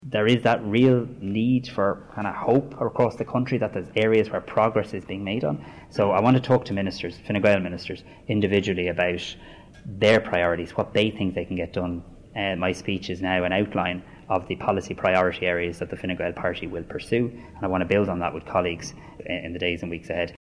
He says he also plans to sit down with Fine Gael ministers in the coming weeks: